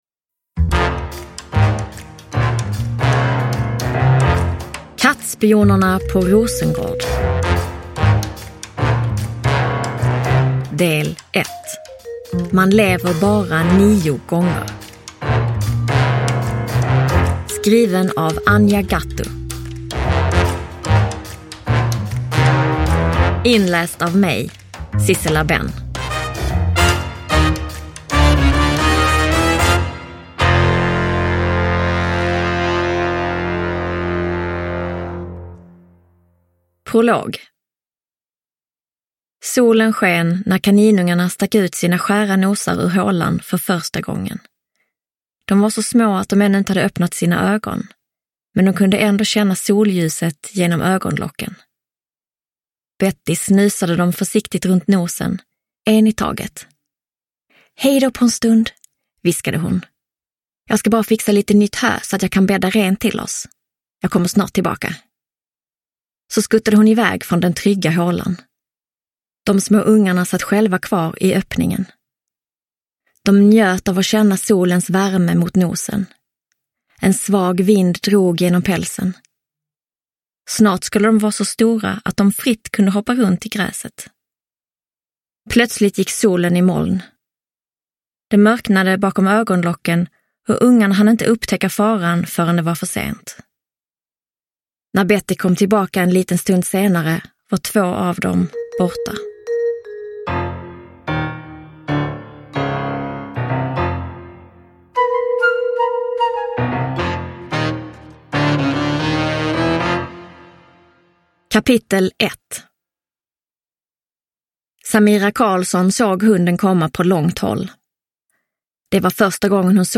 Man lever bara nio gånger – Ljudbok – Laddas ner